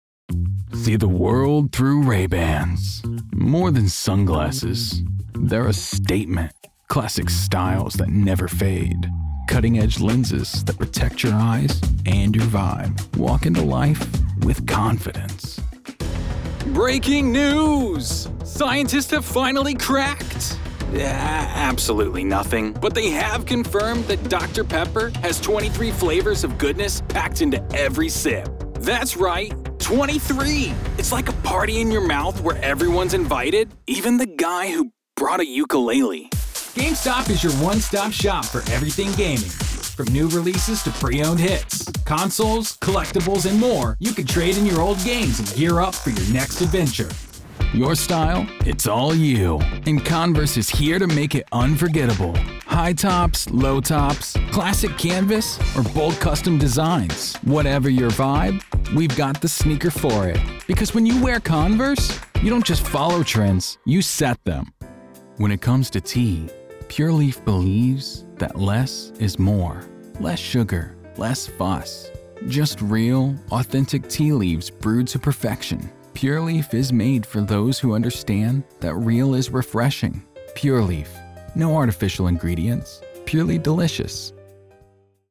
In commercial work, they move easily from calm and conversational to confident and convincing. Their voice can be warm, gritty, or quietly authoritative always connecting without overselling.
COMMERCIAL 💸
broadcast level home studio